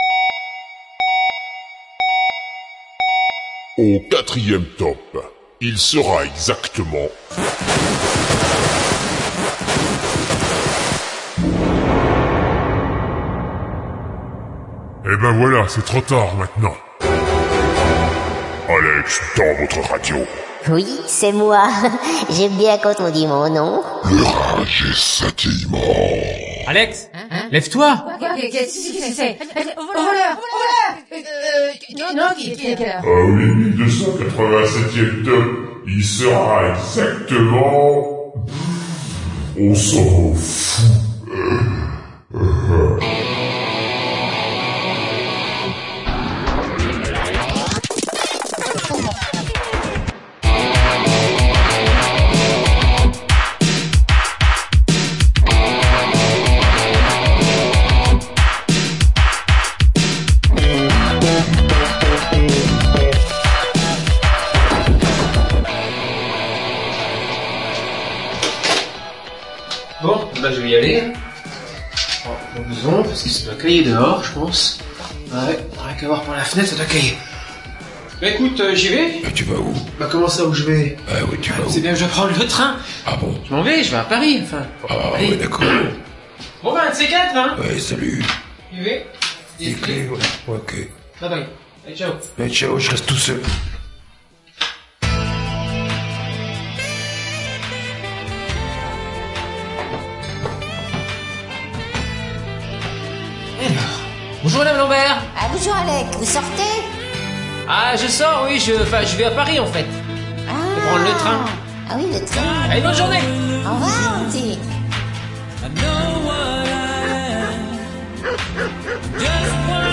�a, c'est pour faire un peu "David Vincent" � la recherche d'un raccourcis� Les morceaux sont presque tous identiques (quelques variations tout de m�me), la qualit� est bien meilleure et les jingles moins pr�sents qu'� l'origine parce que je suis fain�ant, je n'en ai pas refait !